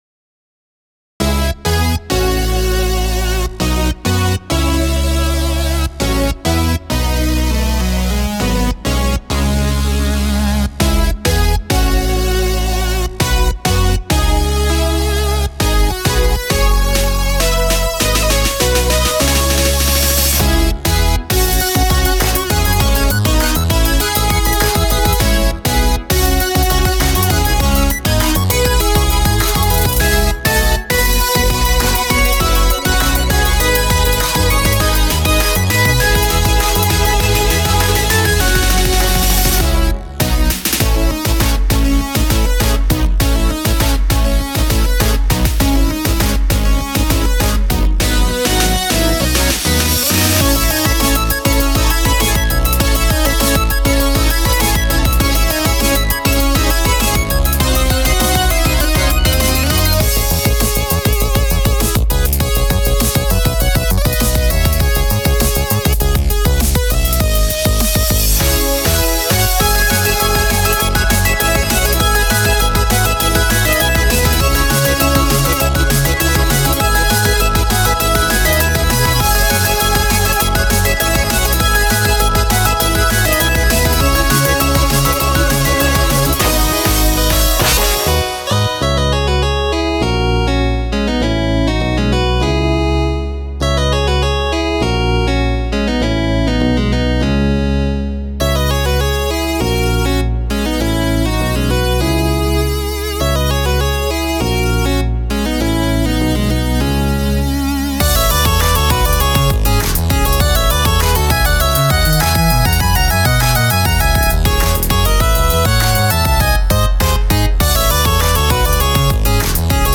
BPM50-200
Audio QualityPerfect (High Quality)
Original BPM: 230